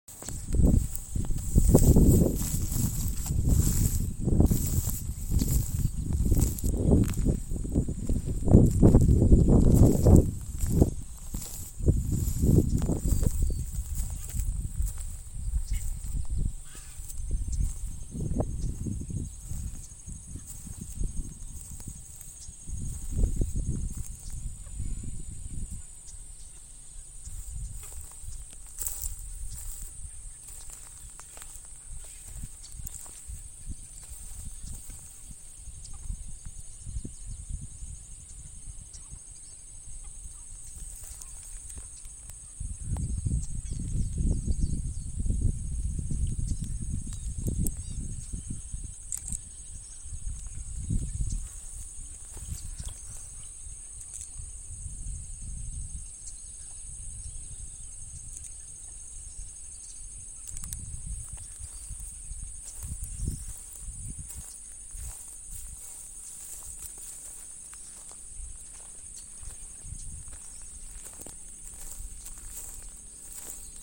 Wren-like Rushbird (Phleocryptes melanops)
Detailed location: Dique Río Hondo
Condition: Wild
Certainty: Recorded vocal